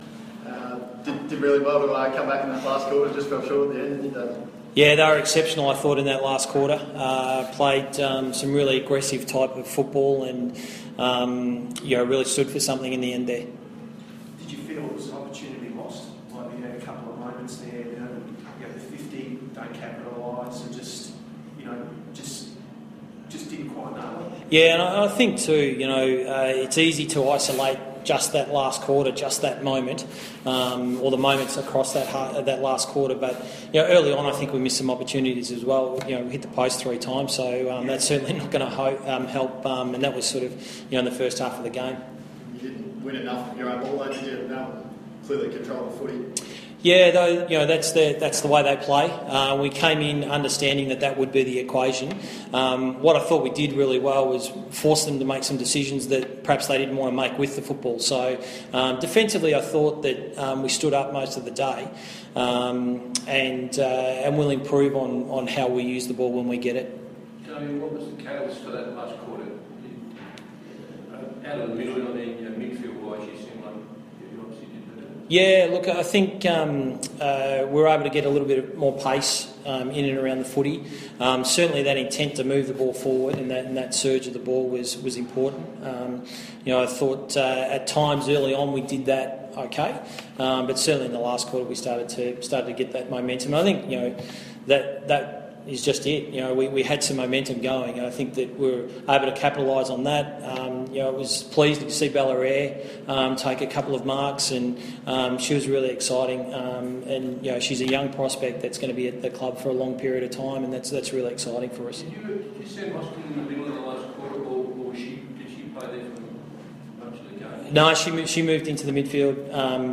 post-match press conference